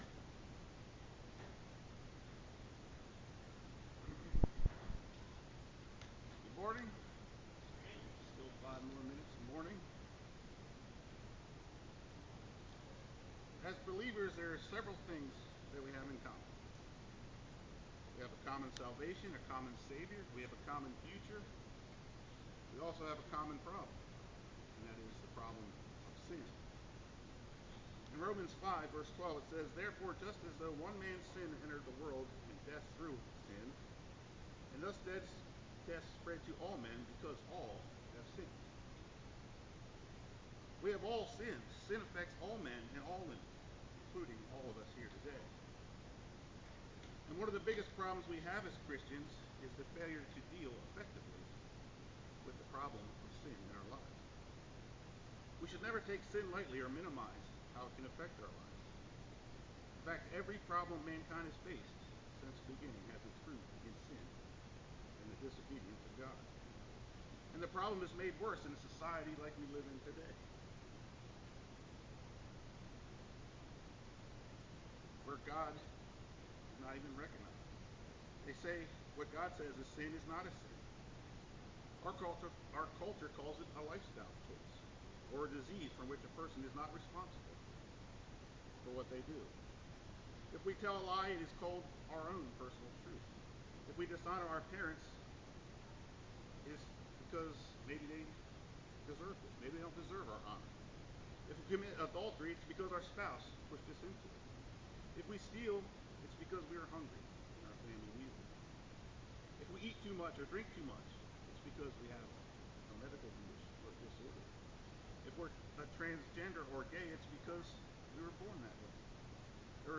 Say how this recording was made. Given in Lewistown, PA